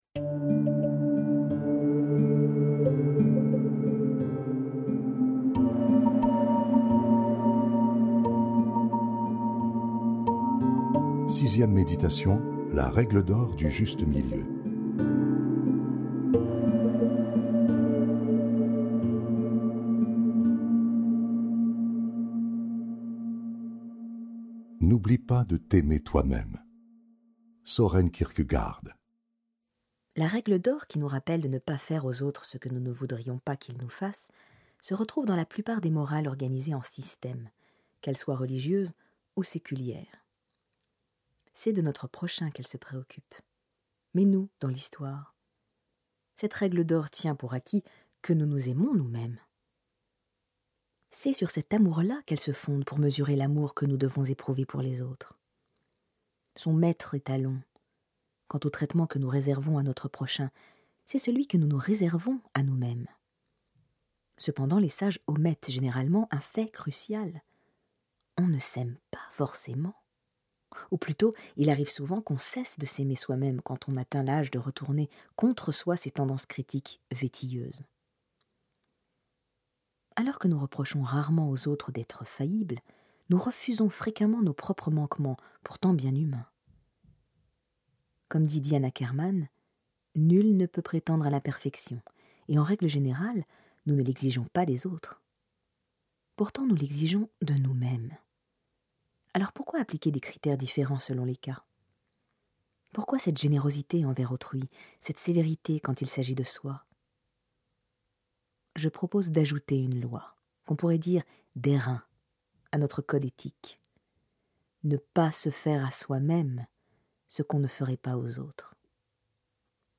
0% Extrait gratuit L’apprentissage de l’imperfection Ne plus avoir peur d’être soi de Tal Ben-Shahar Éditeur : Coffragants Paru le : 2016 Drôle, accessible, rassurant, un petit traité pour se libérer d’un mal insidieux : le perfectionnisme. Après le succès de L’Apprentissage du bonheur, un livre audio de travail indispensable pour apprendre à s’accepter, même imparfait, et être enfin soi-même.